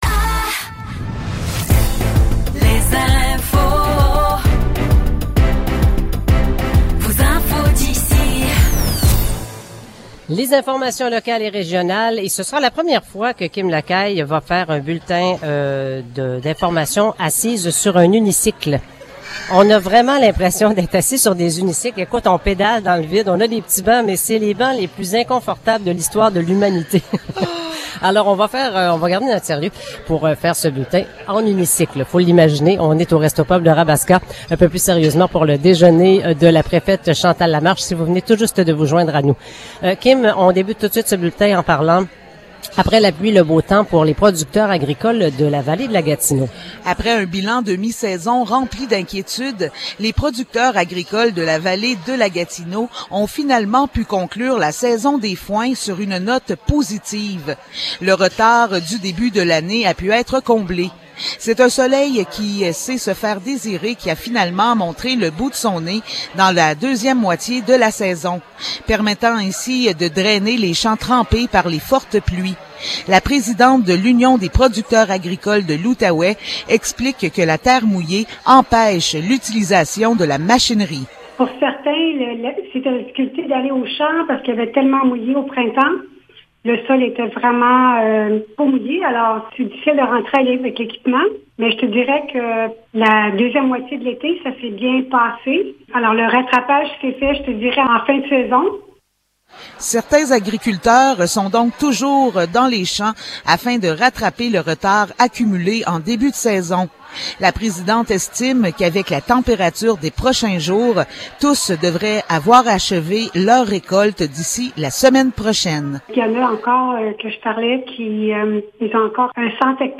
Nouvelles locales - 21 septembre 2023 - 7 h